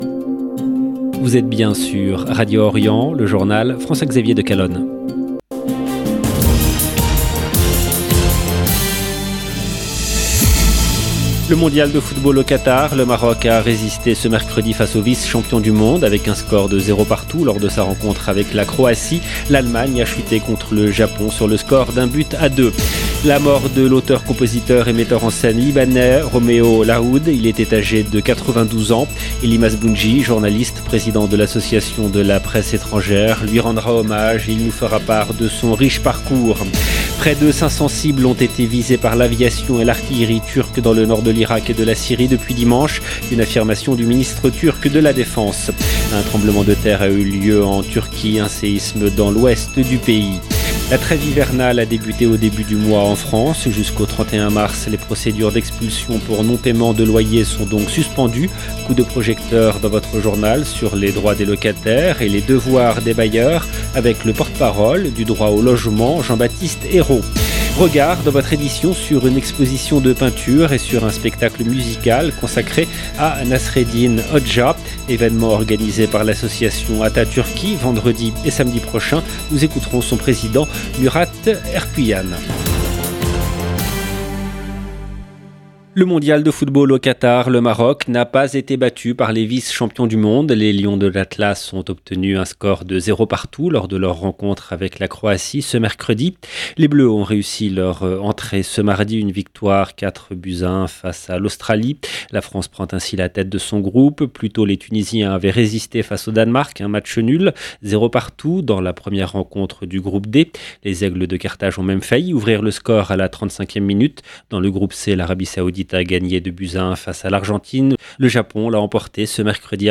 EDITION DU JOURNAL DU SOIR EN LANGUE FRANCAISE DU 23/11/2022